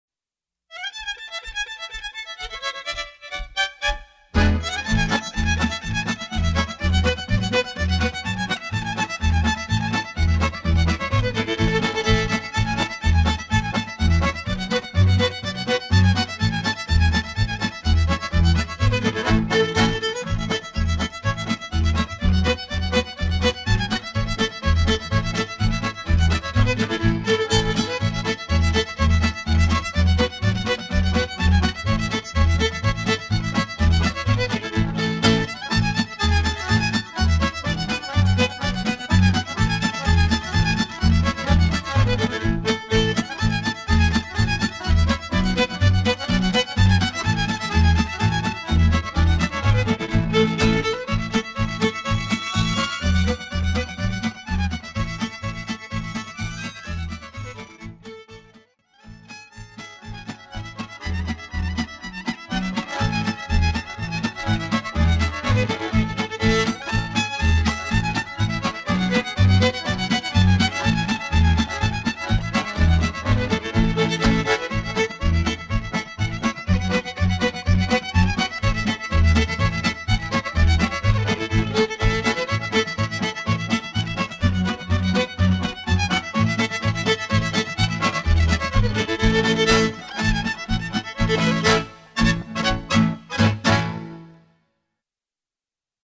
HD = Hoedown/Patter